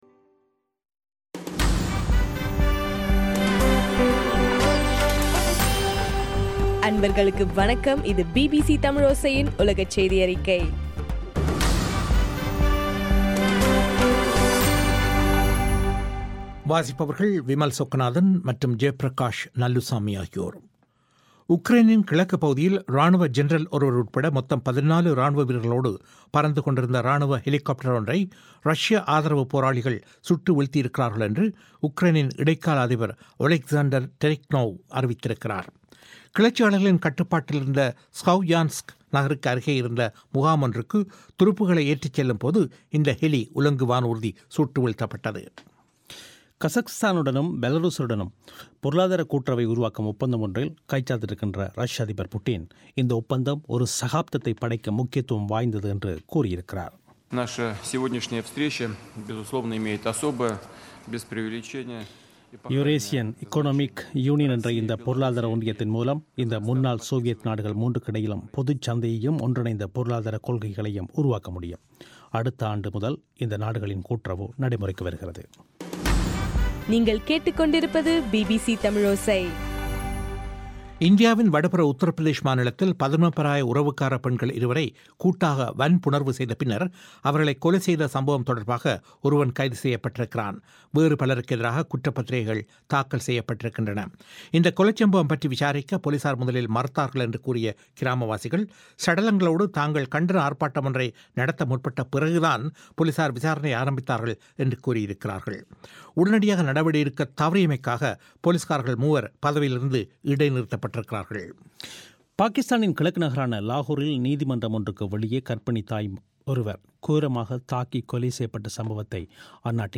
மே 29 - பிபிசி உலகச் செய்திகள்